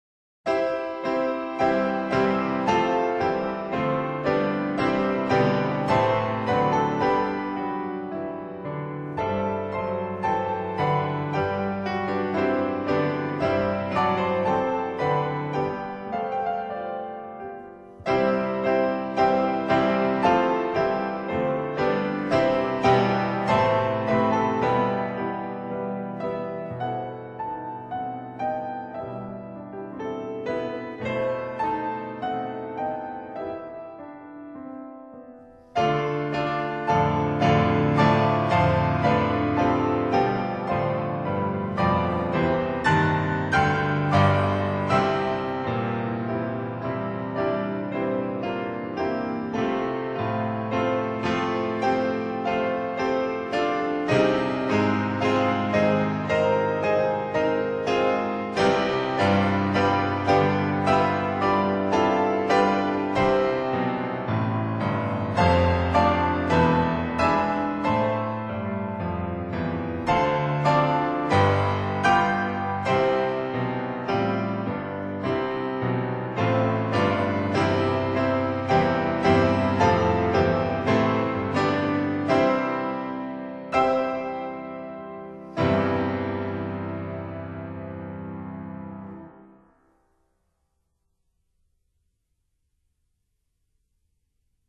Prelude No.1: Allegro maestoso in C major
Piano